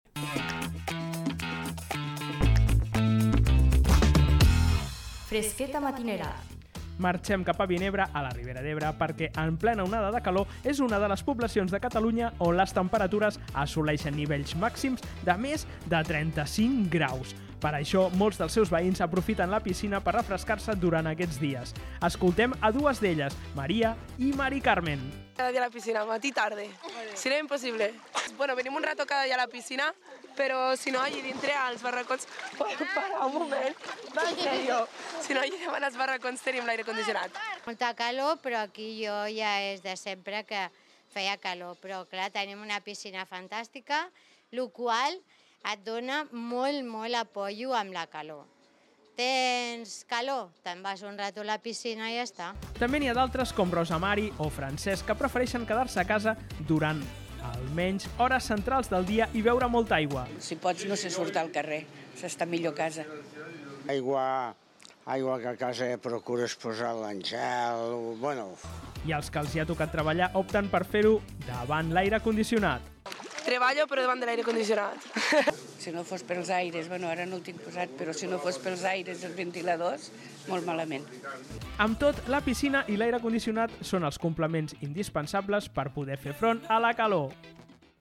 Notícia